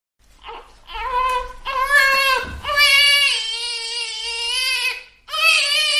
Free Horror sound effect: Baby Crying.
Baby Crying
# baby # cry # unsettling About this sound Baby Crying is a free horror sound effect available for download in MP3 format.
091_baby_crying.mp3